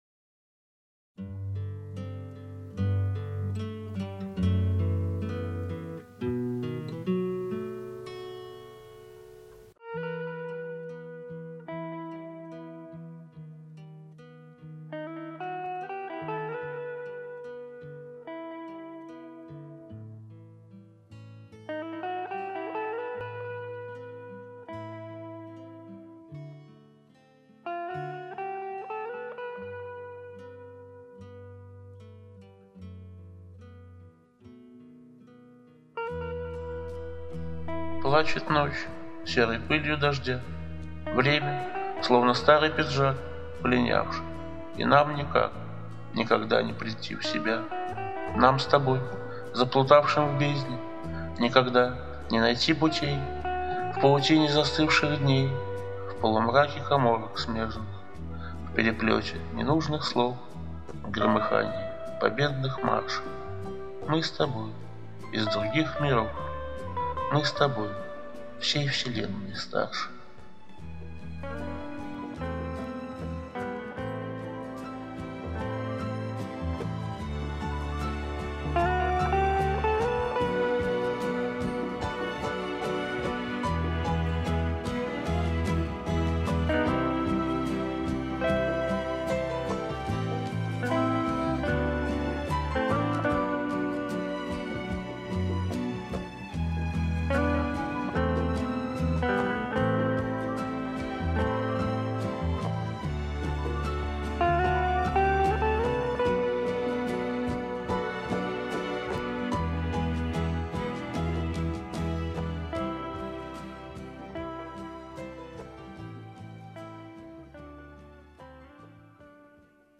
в авторском исполнении